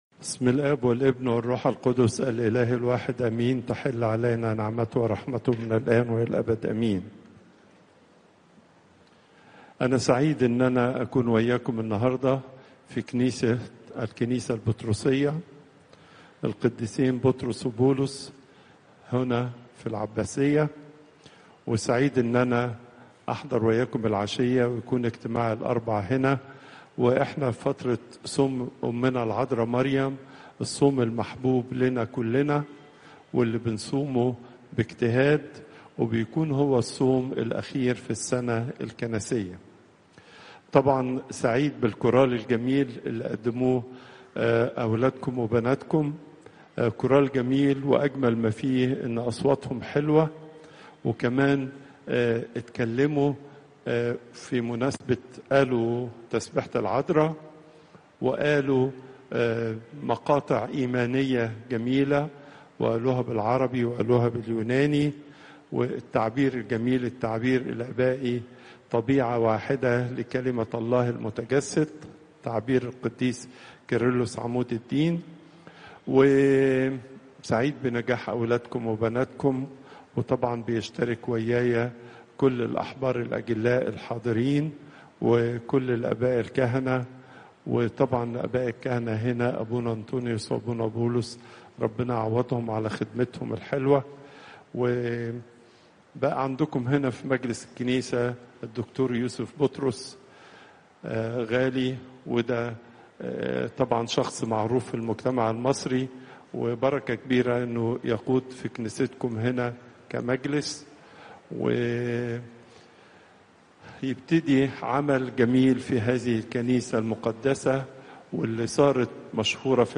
Popup Player Download Audio Pope Twadros II Wednesday, 13 August 2025 43:58 Pope Tawdroes II Weekly Lecture Hits: 340